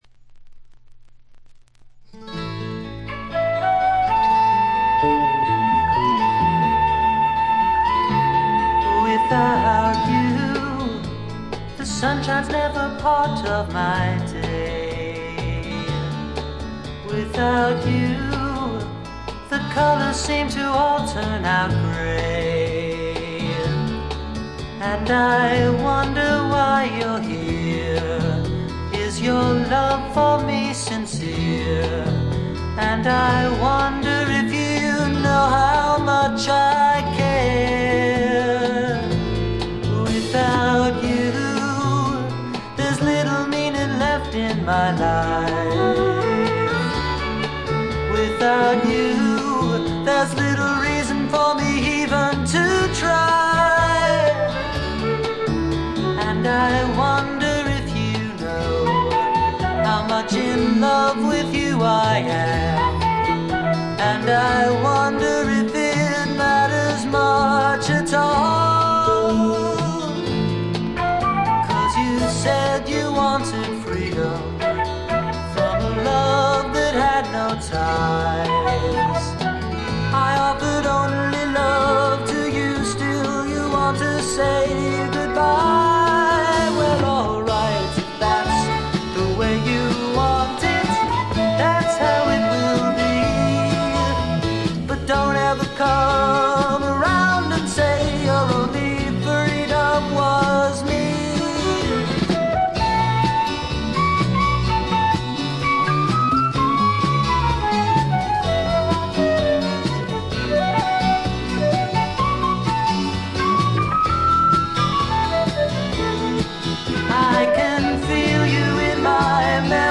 テキサスのシンガー・ソングライターが残した自主制作快作です。。
きらきらときらめくアコギの音、多くのマニアをノックアウトしたメローでクールな楽曲、時おりふっと見せるダウナーな感覚。